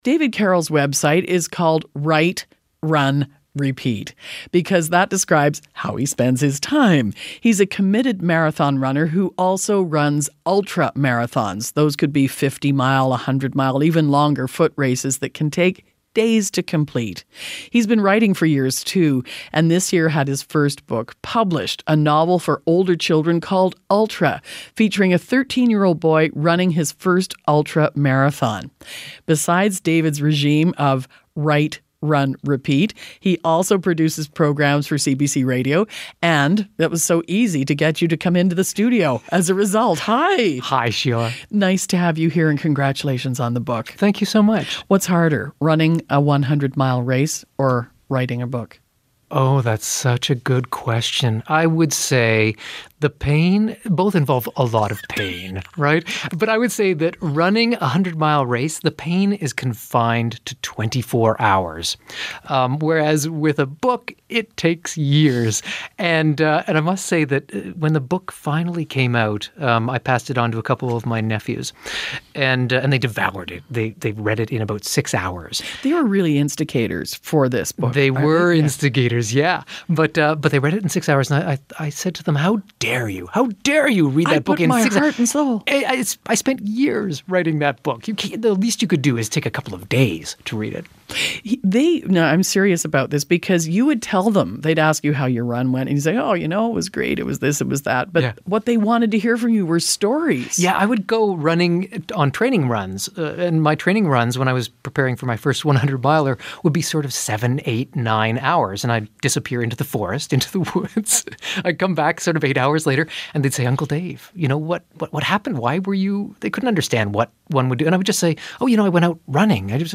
Ultra-Running on the Radio